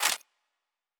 Weapon 12 Foley 2 (Laser).wav